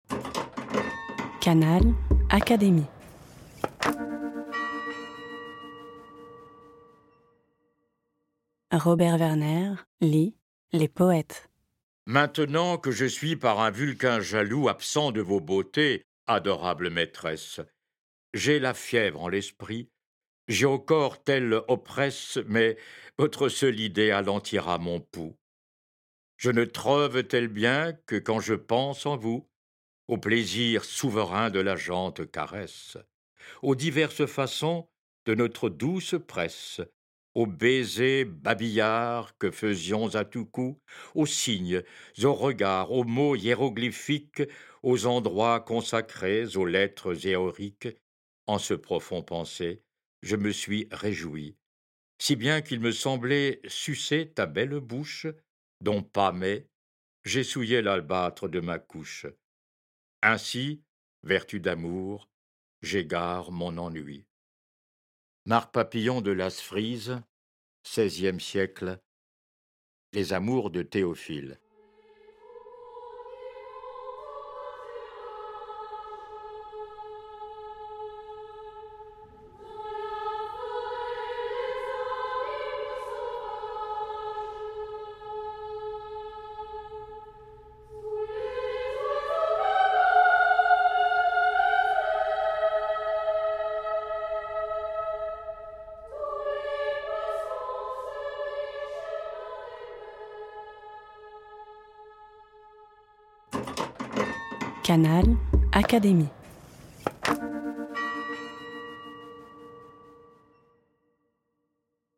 À voix lue